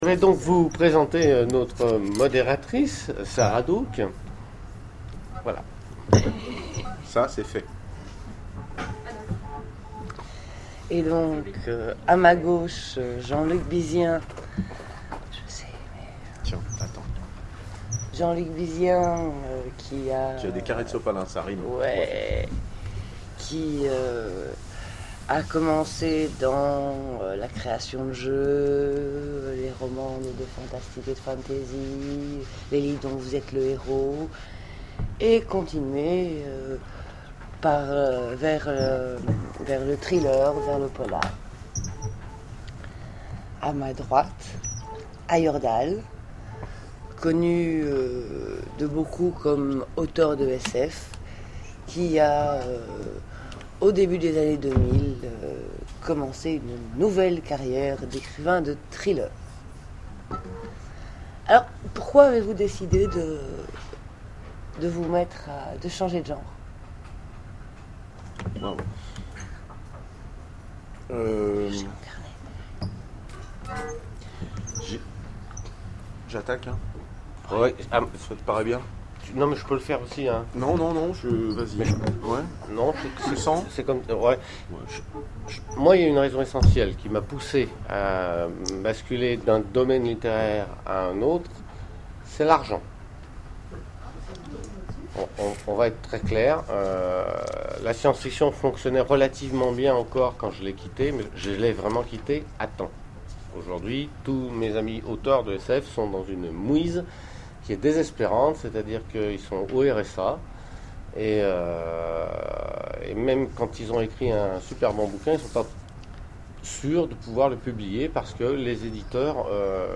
Mots-clés Polar Conférence Partager cet article